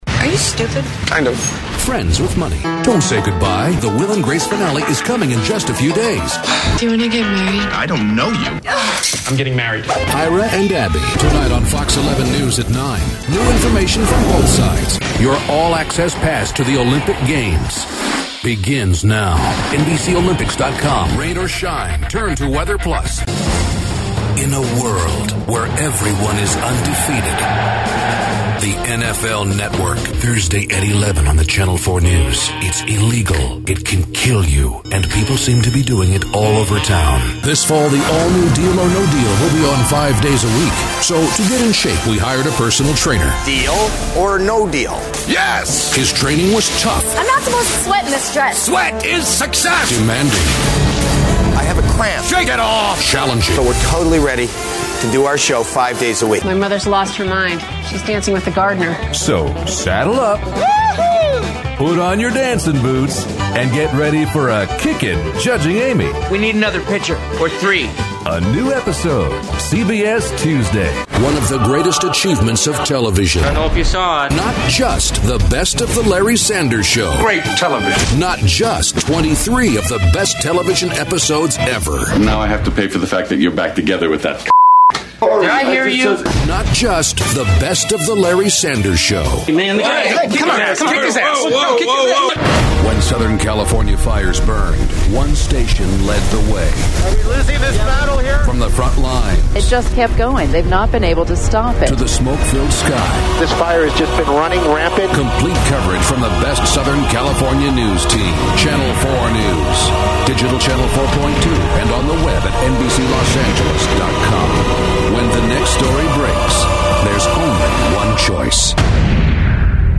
Iconic, Dramatic, Impeccable.
Promo